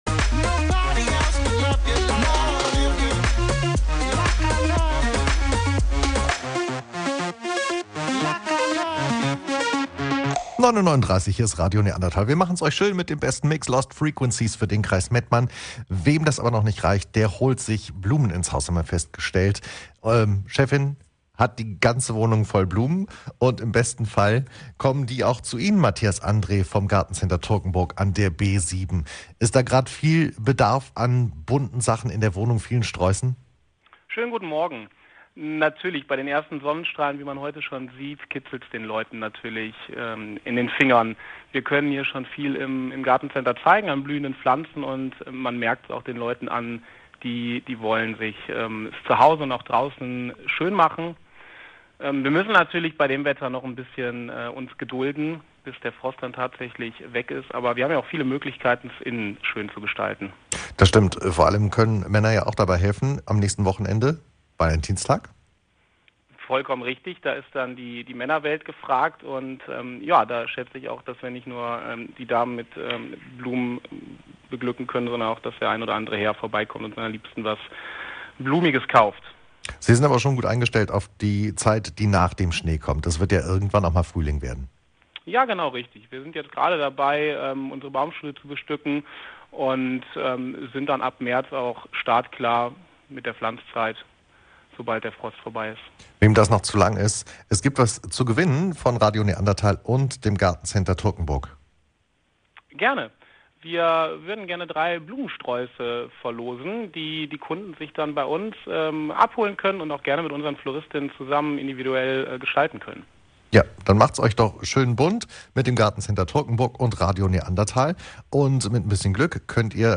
Veröffentlicht: Dienstag, 09.02.2021 10:03 Anzeige Interview Frühlings-Sehnsucht play_circle Abspielen download Anzeige